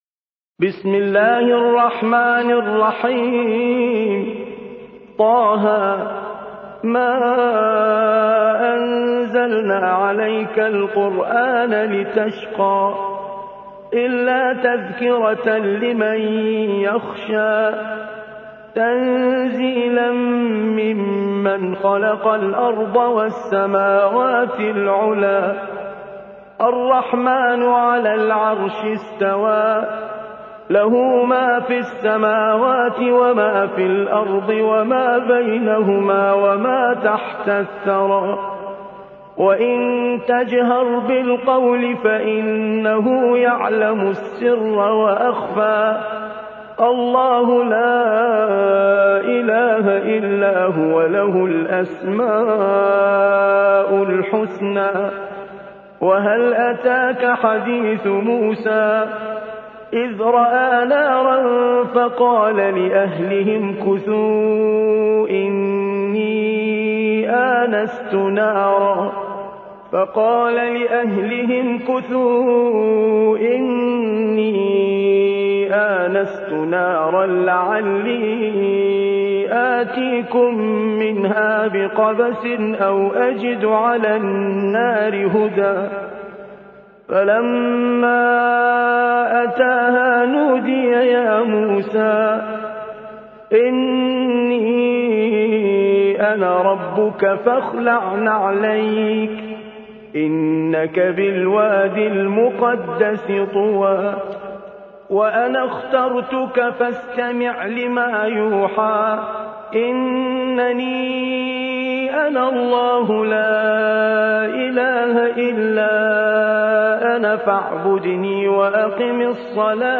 20. سورة طه / القارئ